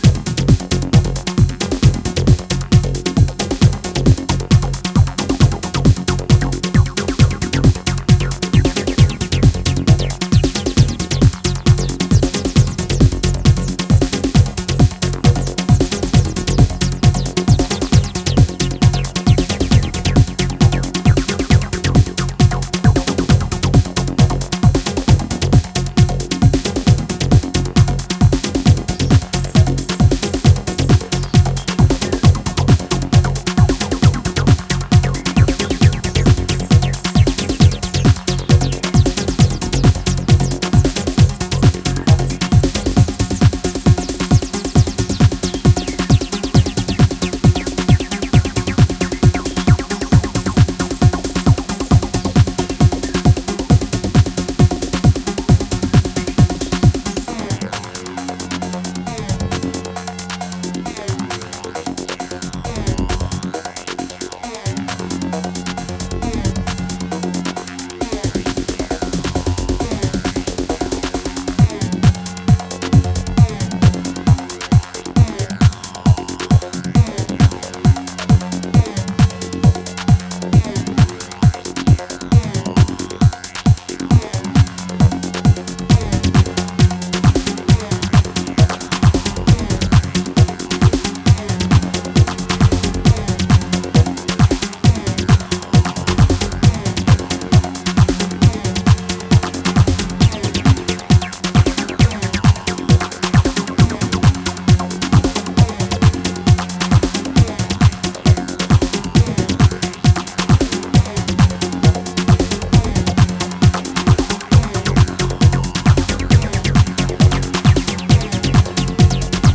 上昇するアルペジオがアップリフティングなテンションをキープする